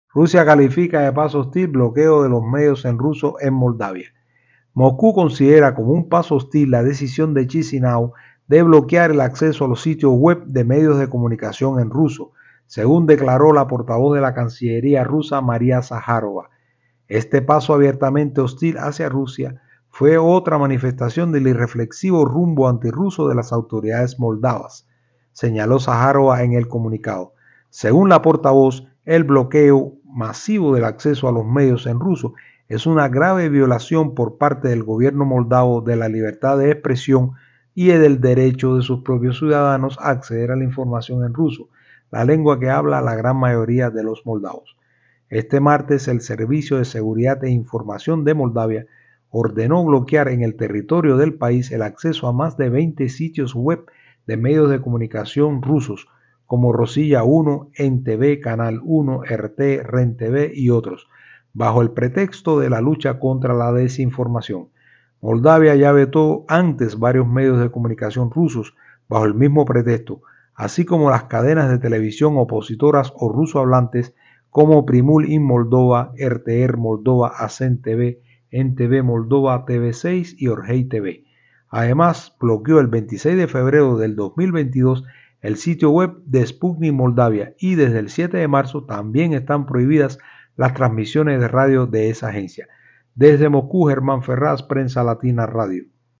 desde Moscú